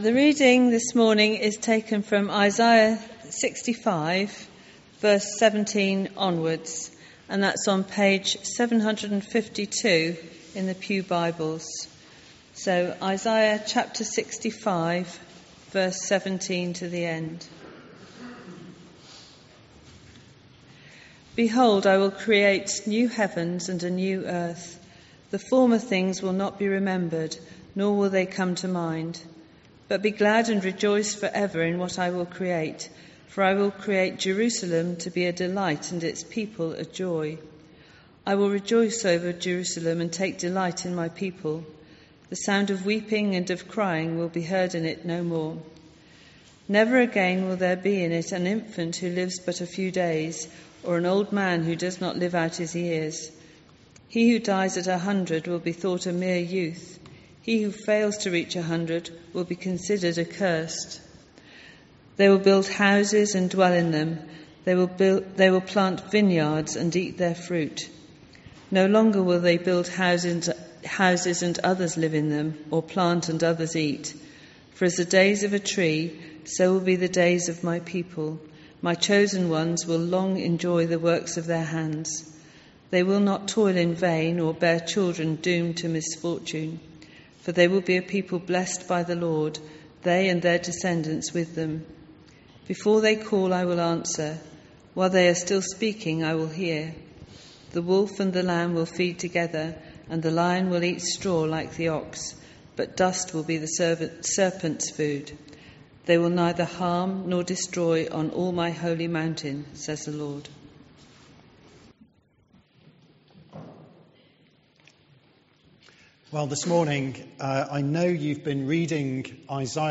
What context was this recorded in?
An audio file of the service is available to listen to.